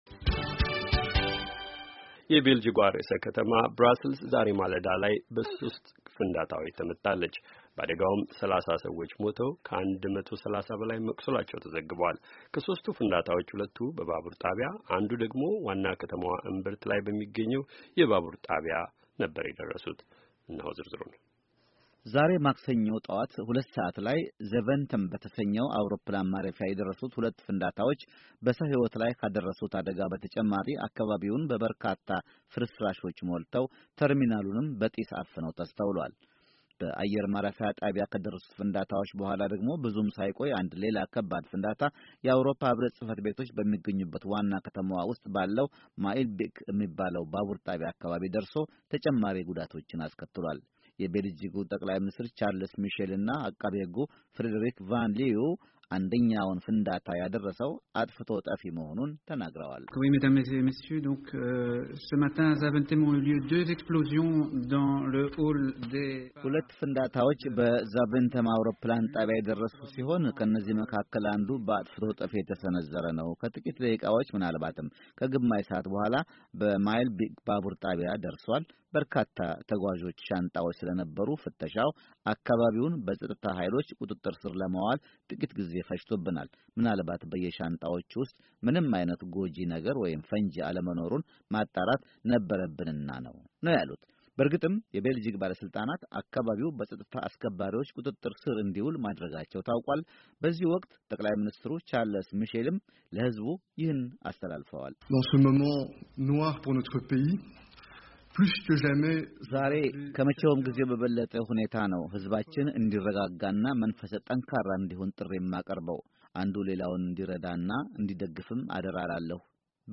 በብራስልስ ስለ ደረሰው ጥቃት የሁለት ኢትዮጵያውያን አስተያየት